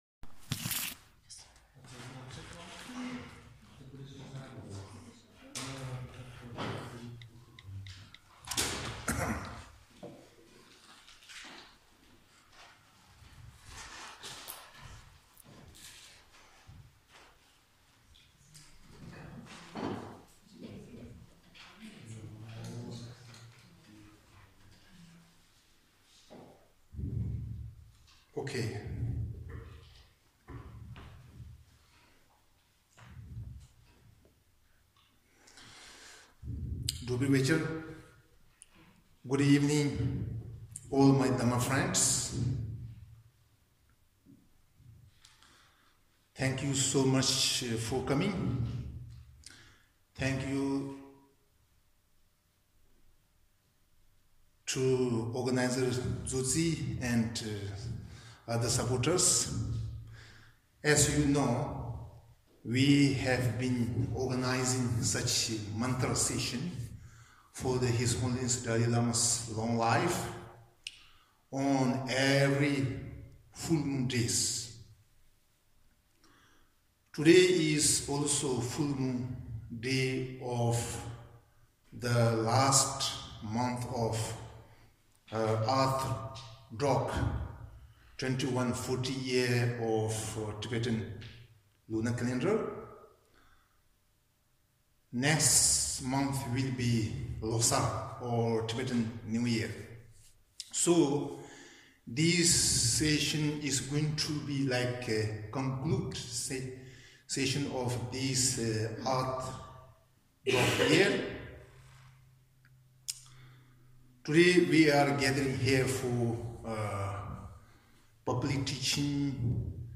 vajrasattvateaching.mp3